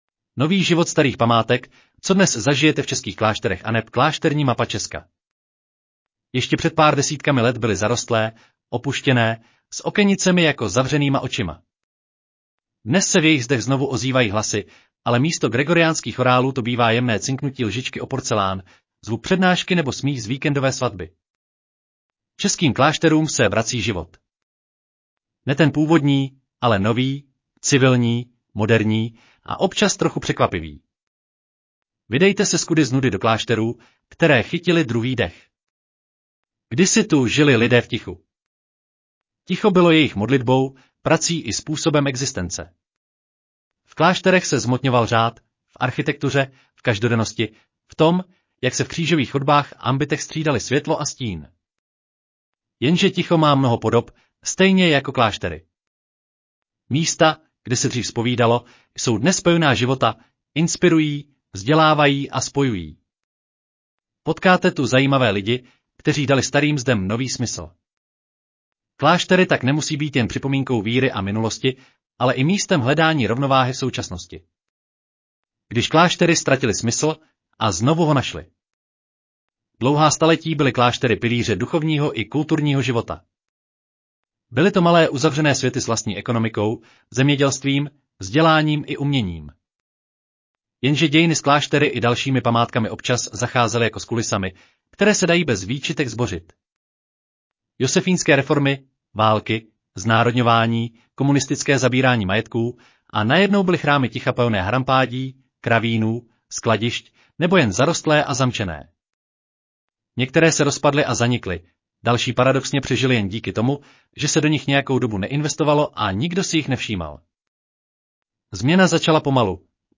Audio verze článku Tradice: dříve klášter, dnes… aneb nový život starých památek